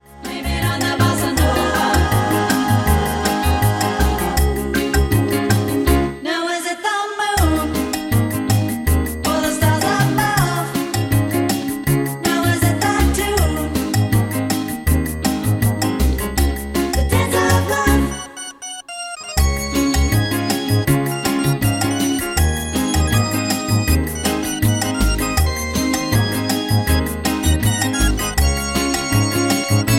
Db
MPEG 1 Layer 3 (Stereo)
Backing track Karaoke
Pop, Oldies, 1960s